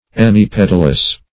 Search Result for " enneapetalous" : The Collaborative International Dictionary of English v.0.48: Enneapetalous \En`ne*a*pet"al*ous\, a. [Gr.